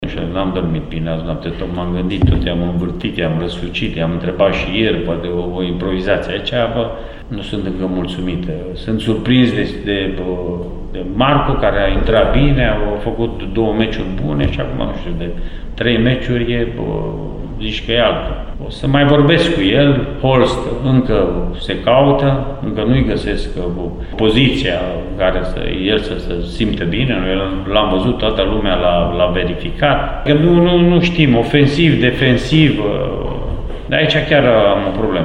Antrenorul Bătrânei Doamne, Mircea Rednic, spune că încă are dificultăți în a alege un prim 11 ceva mai legat, în condițiile în care evoluțiile recente sunt oscilante: